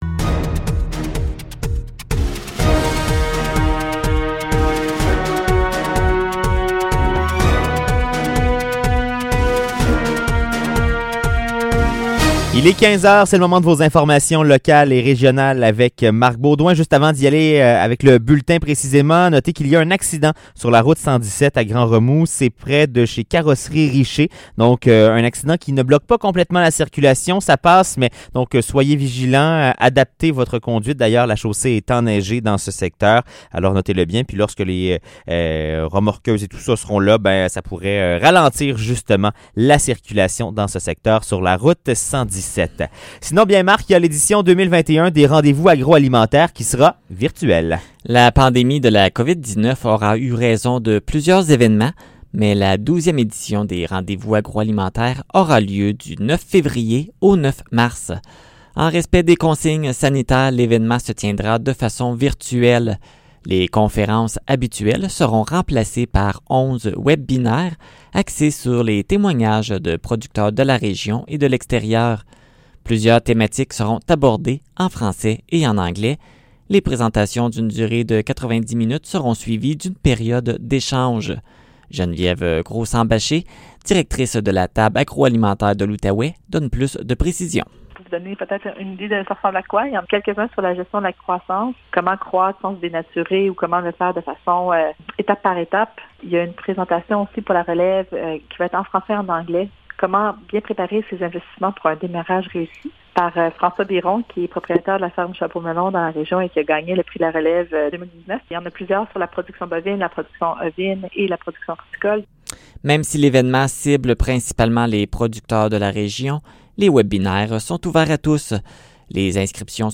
Nouvelles locales - 5 février 2021 - 15 h